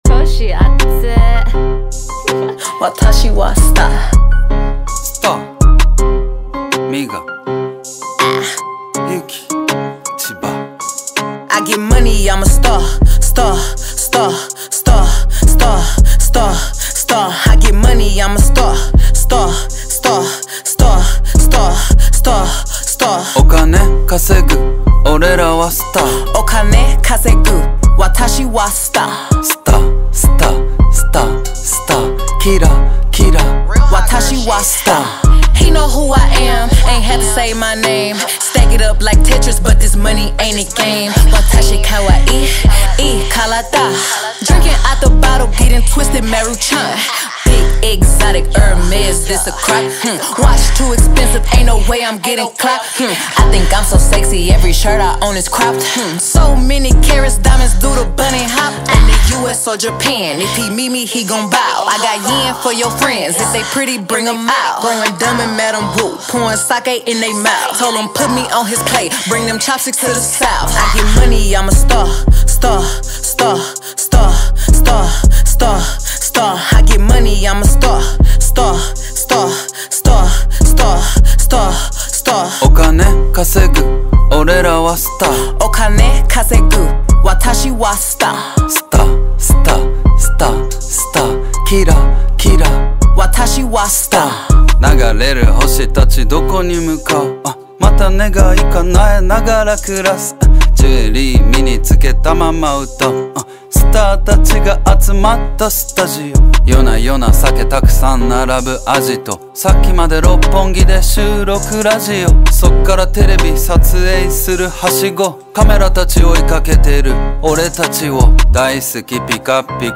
2024-08-22 19:58:02 Gênero: Hip Hop Views